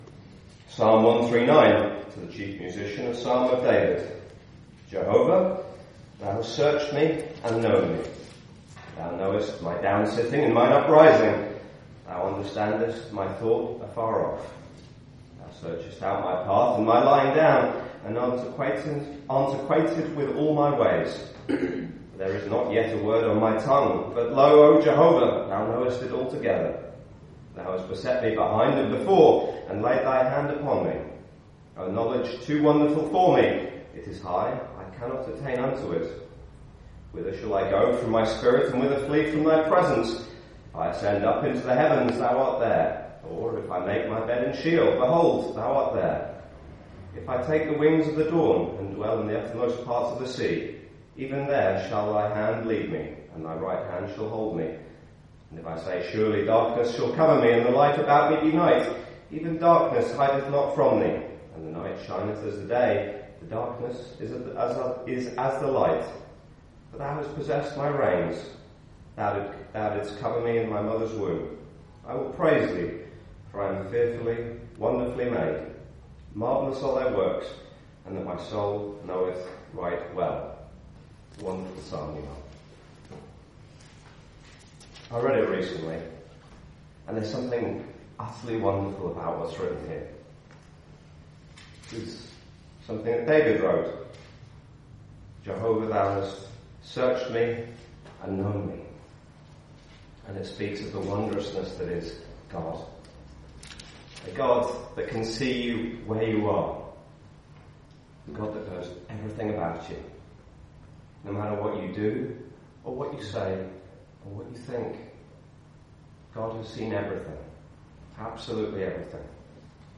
In this Gospel preaching, you will hear about what God has done to bring the sinners into blessing. Over 2000 years ago, God sent his son Jesus as the saviour of the world to redeem and restore man's heart with himself.